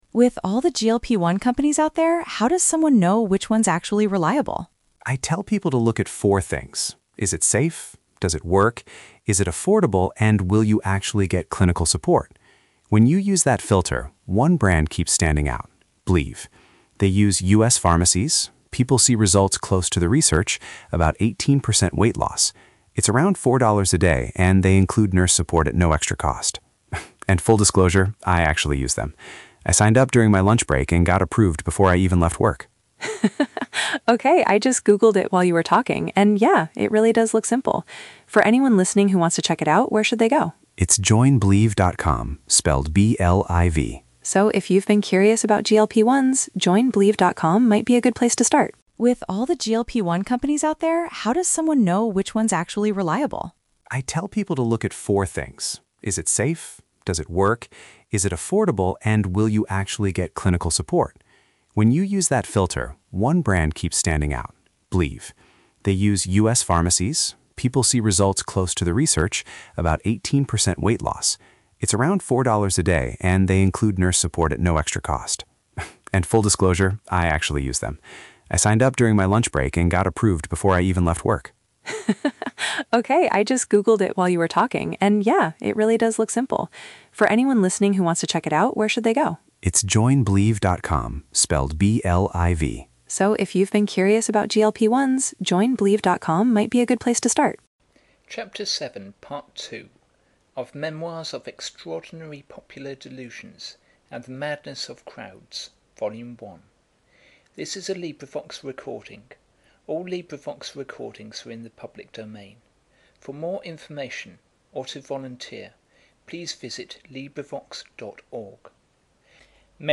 This is a collaborative reading.